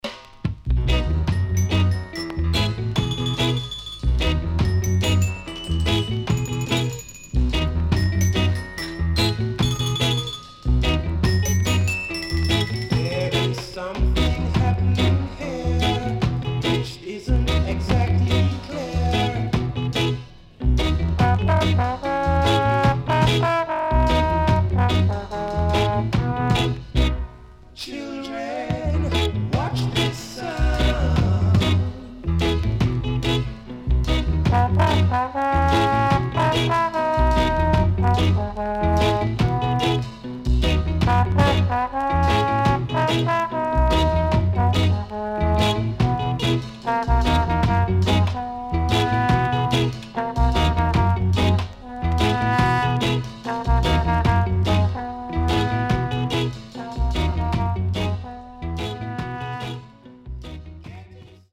HOME > SKA / ROCKSTEADY  >  ROCKSTEADY  >  INST 60's
Nice Rocksteady Inst
SIDE A:所々チリノイズがあり、少しプチノイズ入ります。